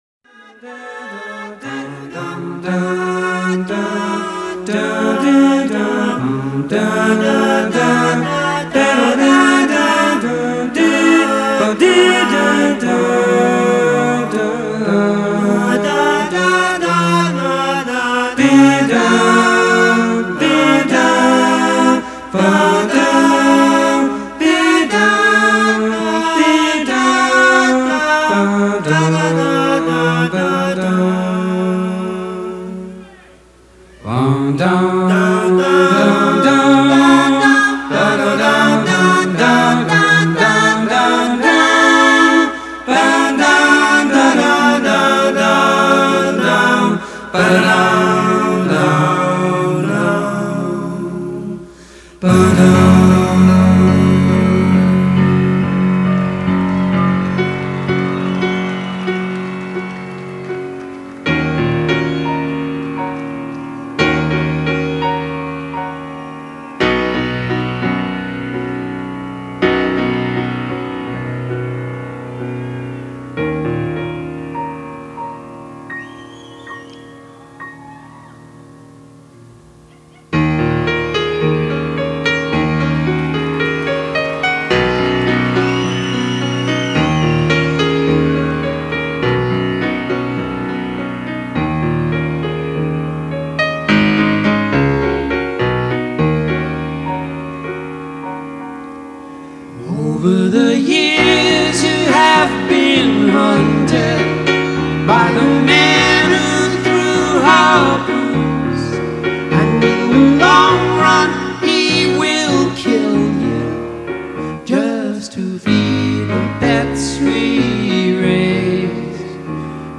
live at Musical Theatre, Sunrise Florida, Jan 18,1992.